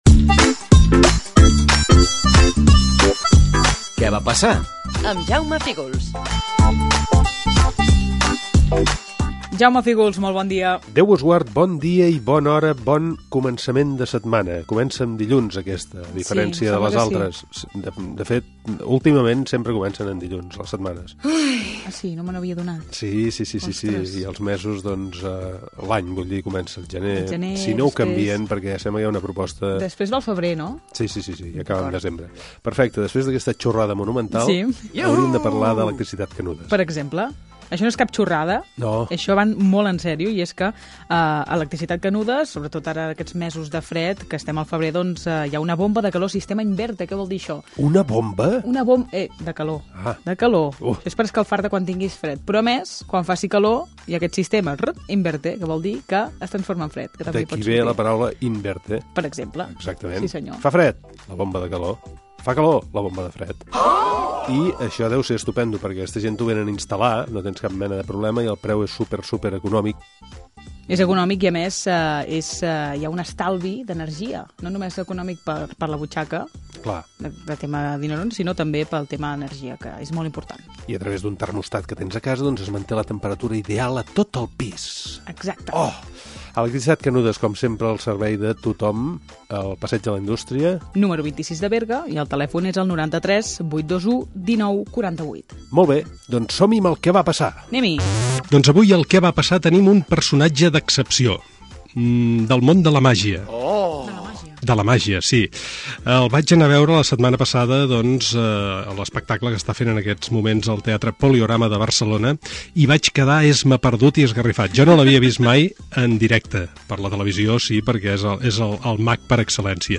Espai "Què va passar?". Careta del programa, publicitat i entrevista telefònica al mag Juan Tamariz
Entreteniment